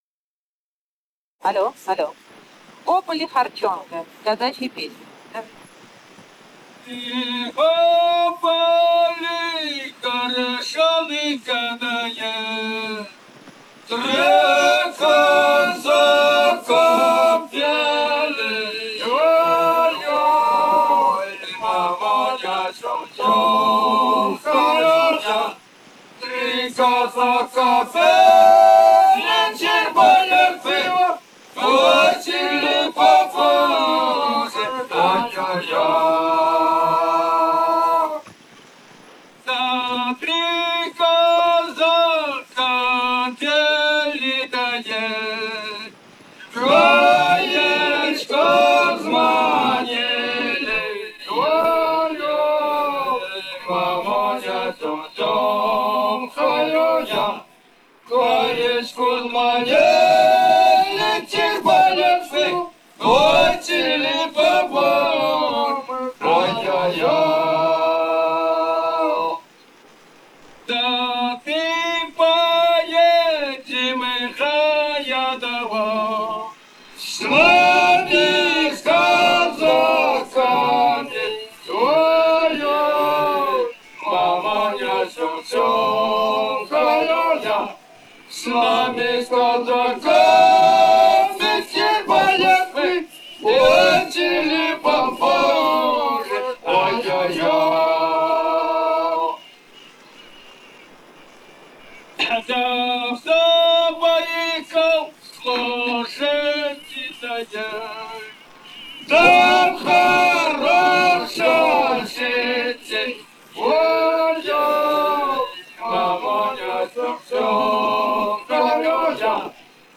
«Во поле корчонка» (лирическая «казачья»).
Бурятия, с. Желтура Джидинского района, 1966 г. И0903-20